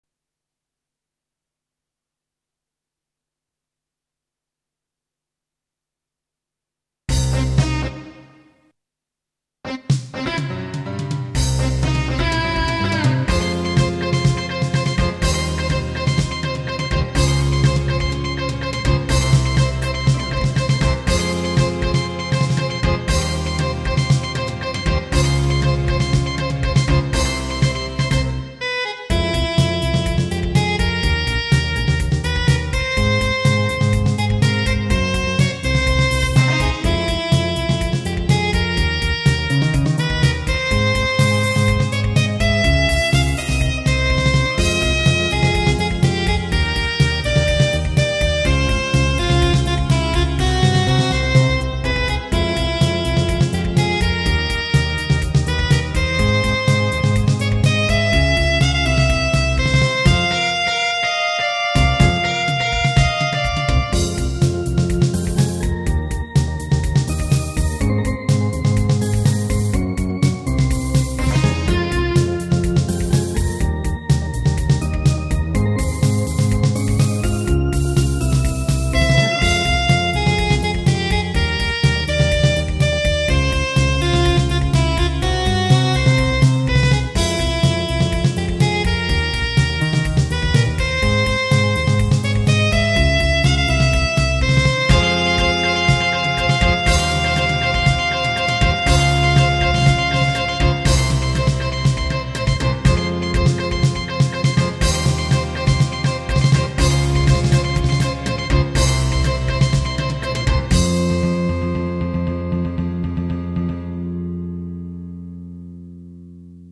アタマちょっと途切れてるのは、ブラウザ最小化→元のサイズに戻す ってやってんだけど
昨日の病院のWebのBGMなんだけど、